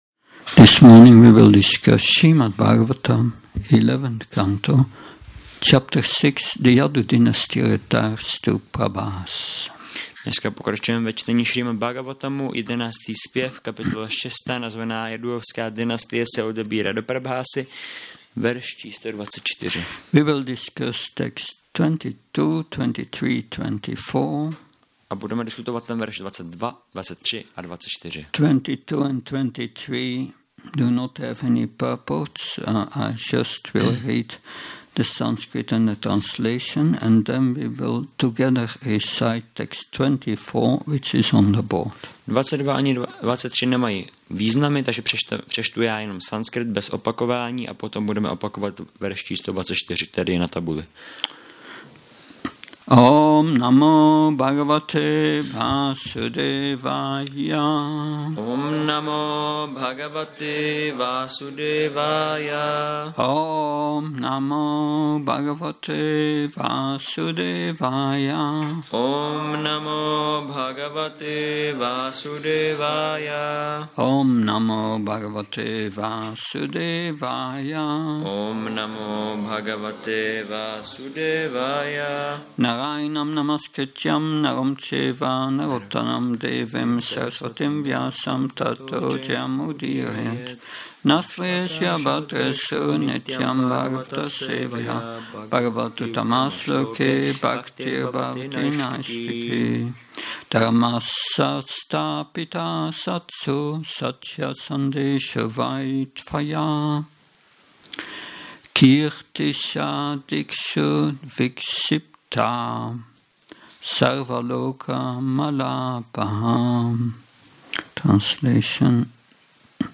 Šrí Šrí Nitái Navadvípačandra mandir